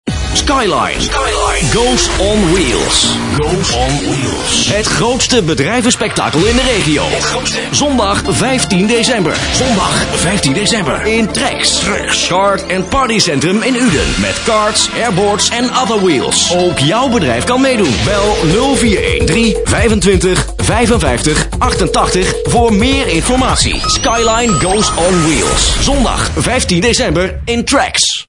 Commercials
Op deze pagina tref je een aantal voorbeelden aan van commercials die de afgelopen tijd door mij zijn ingesproken voor diverse lokale-, regionale- en interrnet-radiostations.